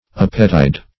Opetide \Ope"tide`\, n. [Ope + tide.]